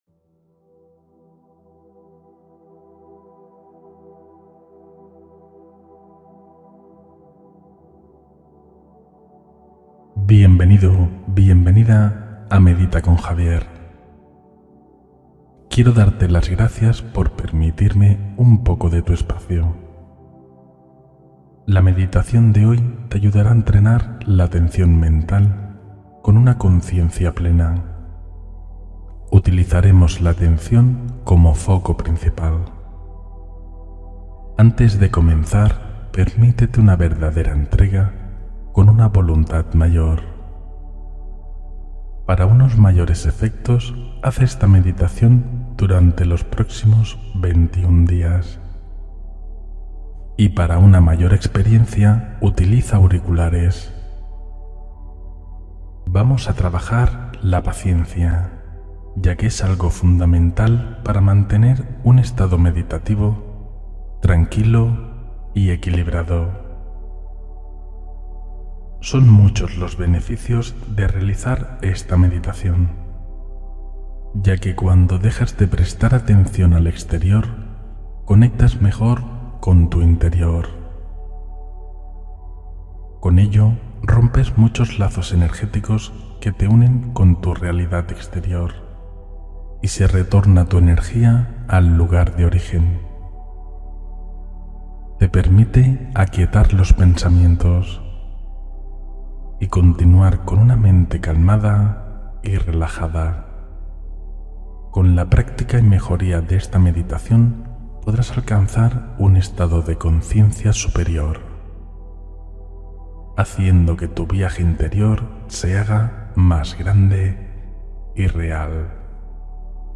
Relájate y cultiva paciencia con esta meditación restauradora – Día 13